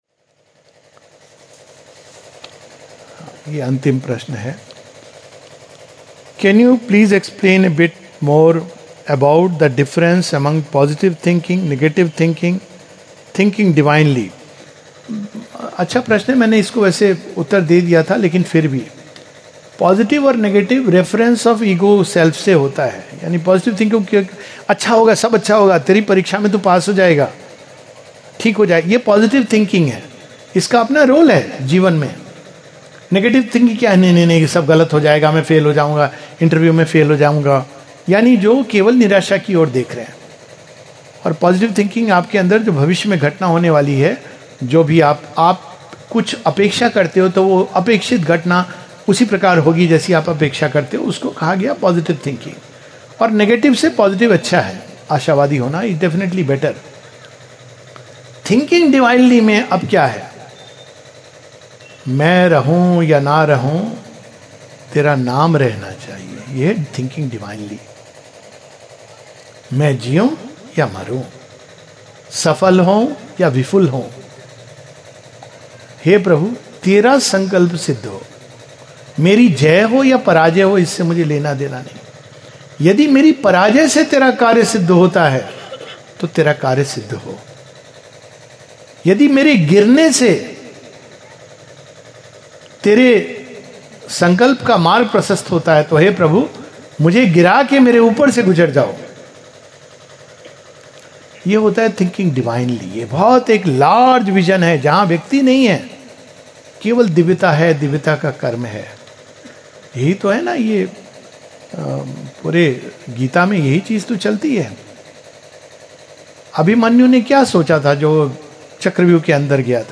[Positive and Negative Thinking] From Q&A at Sri Aurobindo Gram, Mehsua, MP